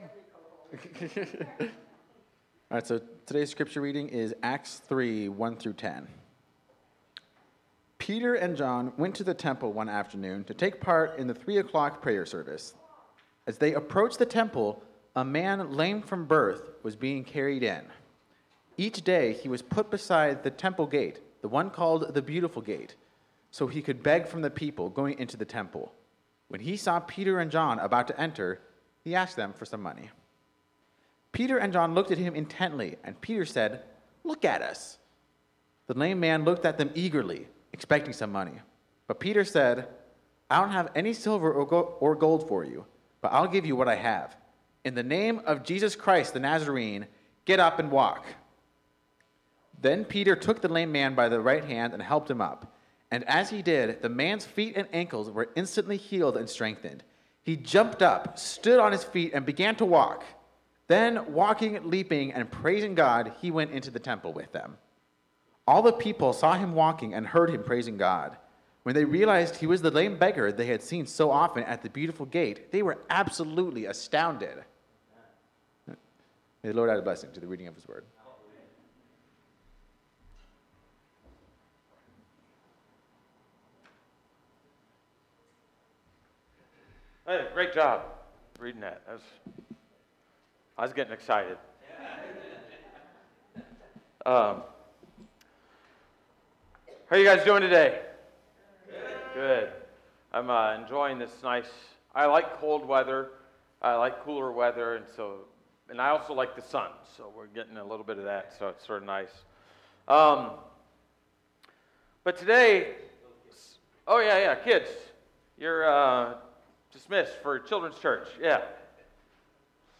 Seeing With Jesus- the Beggar at the Gate - Grace Christian Fellowship